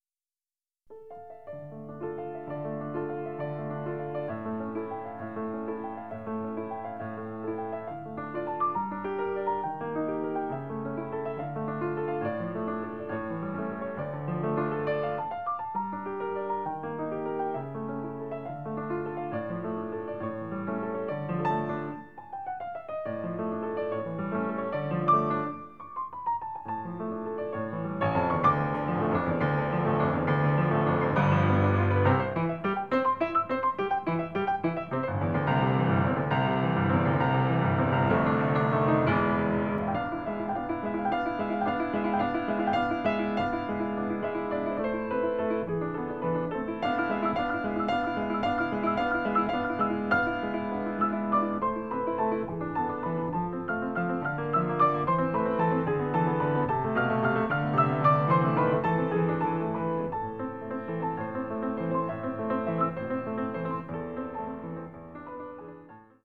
La sonata - Corso di studio svolto al Conservatorio "G.Verdi" di Torino nel maggio del 2000 dedicato alla "sonata pianistica", nell'ambito del potenziamento e della formazione musicale e professionale degli allievi.